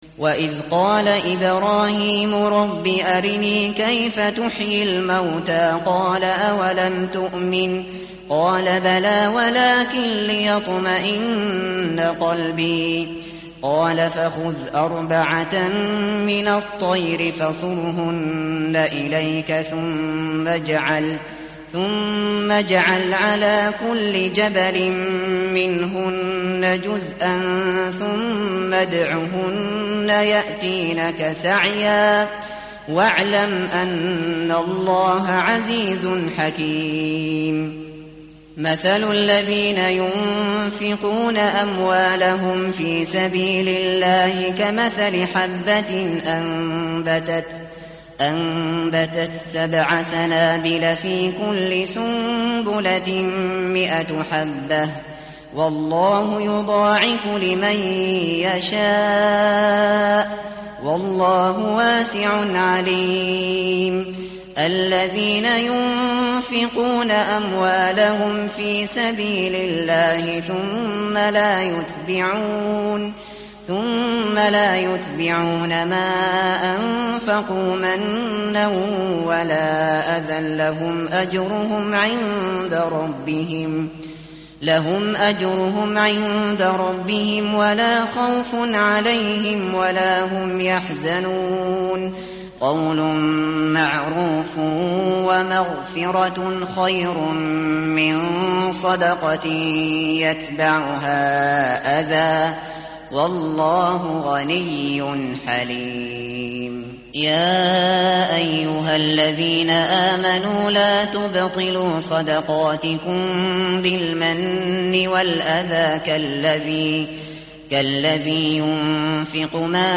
tartil_shateri_page_044.mp3